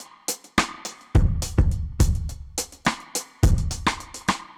Index of /musicradar/dub-drums-samples/105bpm
Db_DrumsB_Wet_105-03.wav